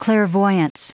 Prononciation du mot clairvoyance en anglais (fichier audio)
Prononciation du mot : clairvoyance